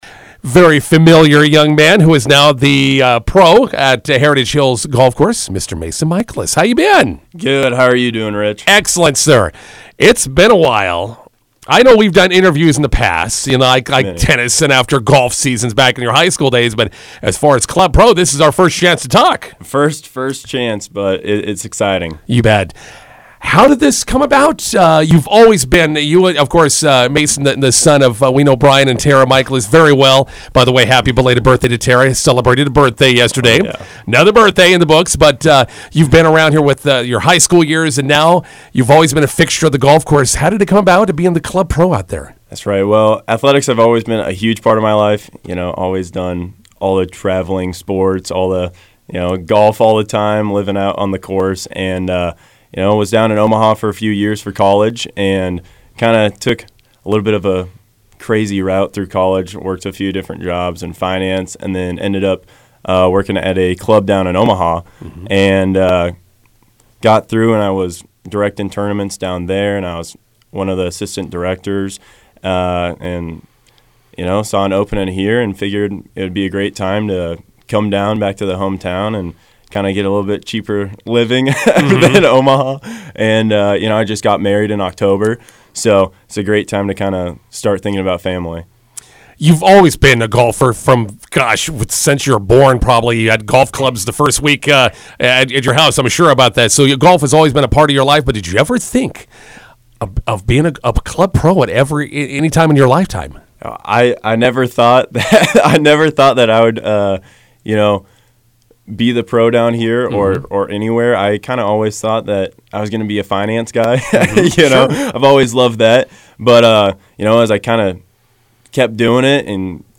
INTERVIEW: Heritage Hills Golf Course off and running with spring/summer events.